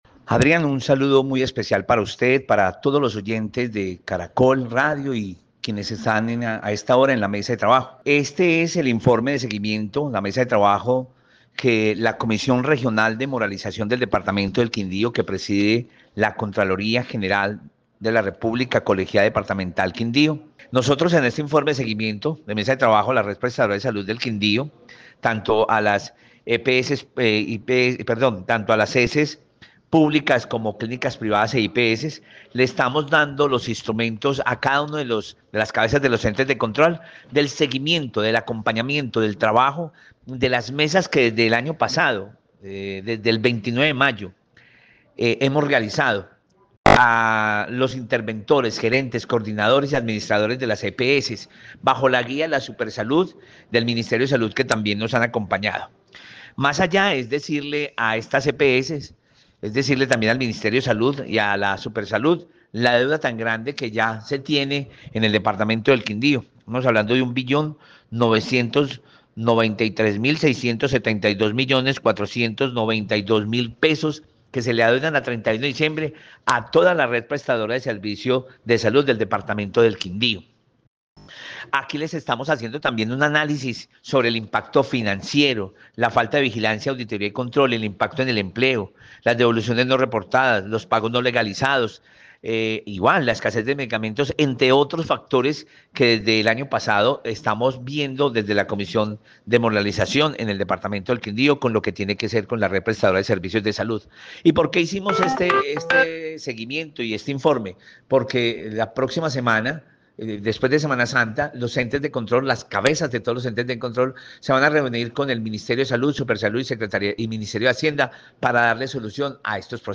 Héctor Marín, gerente de la Contraloría, Quindío